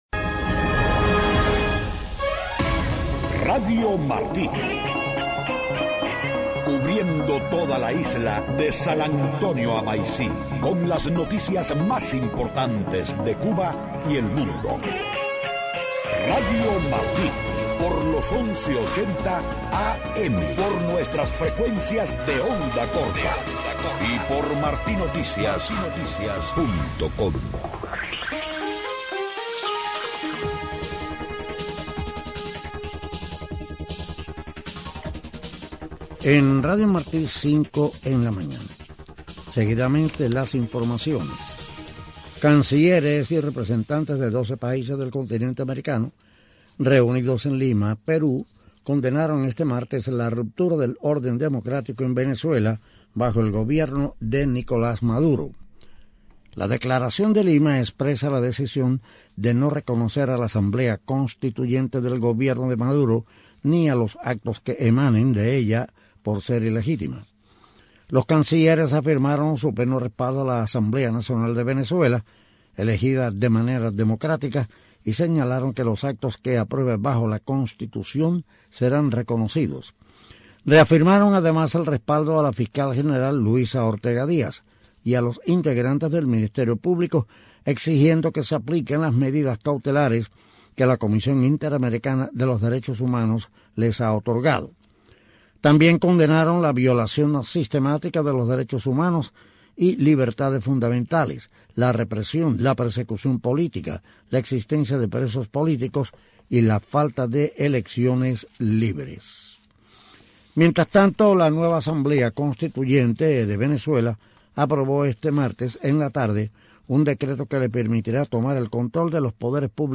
Un programa con mucha aceptación y participación de la audiencia.